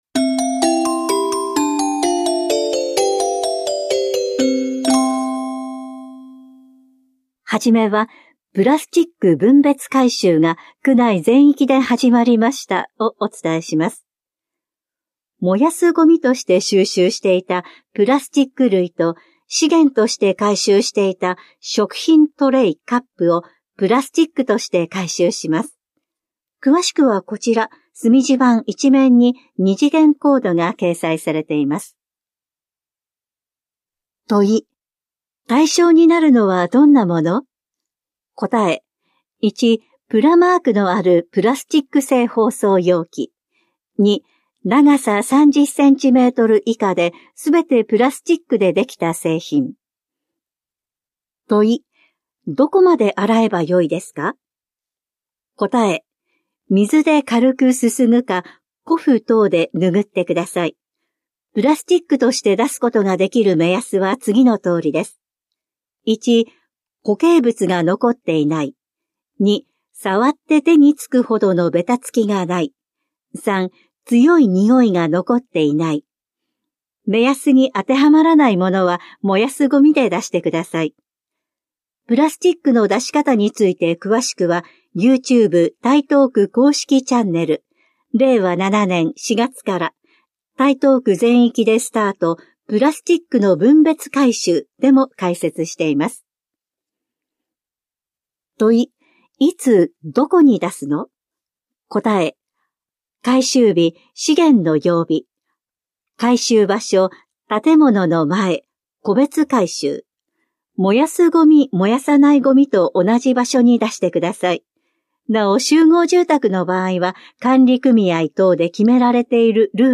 広報「たいとう」令和7年4月5日号の音声読み上げデータです。